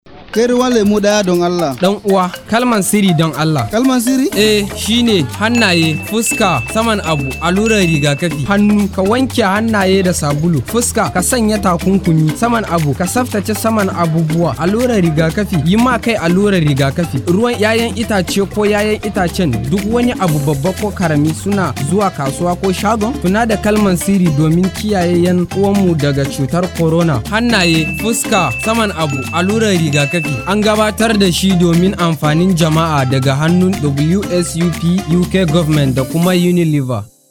Sample Voice for Hausa Language Voice Over
Radio-Juice-seller-Hausa.mp3